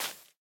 Minecraft Version Minecraft Version 25w18a Latest Release | Latest Snapshot 25w18a / assets / minecraft / sounds / block / big_dripleaf / step3.ogg Compare With Compare With Latest Release | Latest Snapshot
step3.ogg